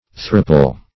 Search Result for " thropple" : The Collaborative International Dictionary of English v.0.48: Thropple \Throp"ple\, n. [Cf. Thrapple , and see Throttle .]